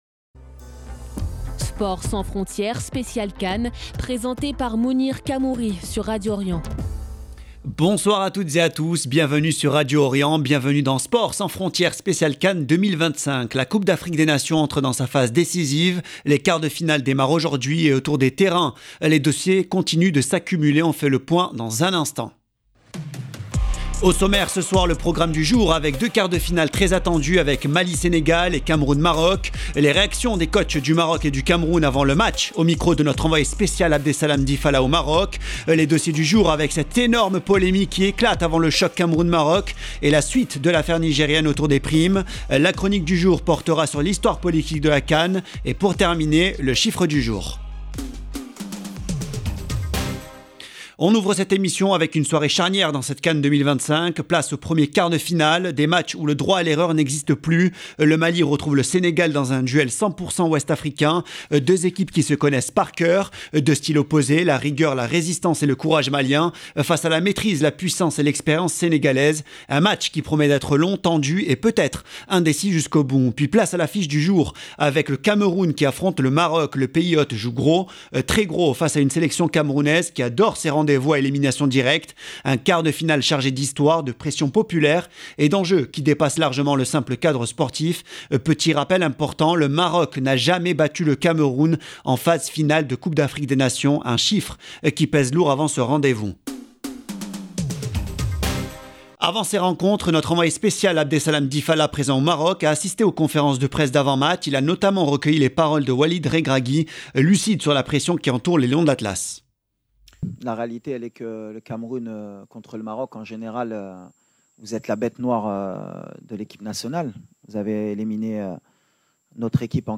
Sport sans frontières - émission spéciale pour la CAN